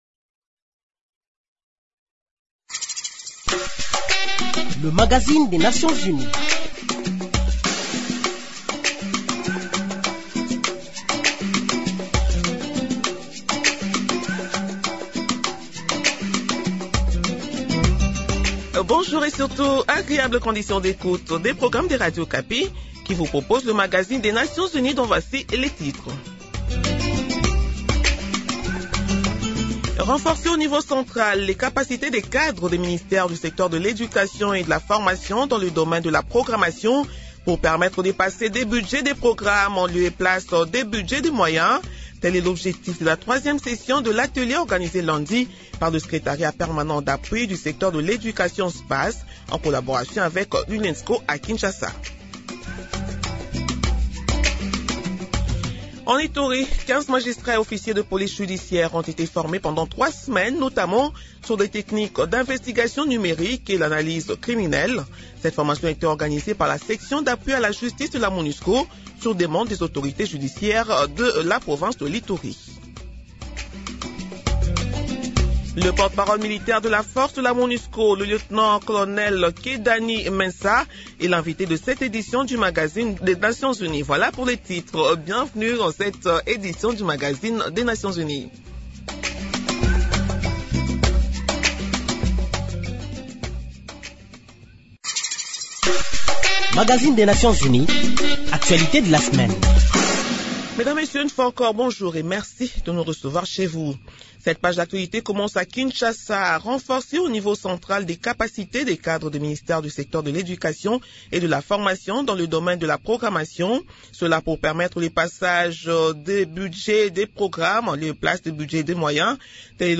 Nouvelles en bref Au Nord-Kivu, 25 artistes musiciens, peintres et stylistes de la ville de Beni ont été sensibilisés mardi 4 juin à la lutte contre la désinformation.